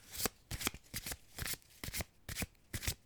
shuffle_0.mp3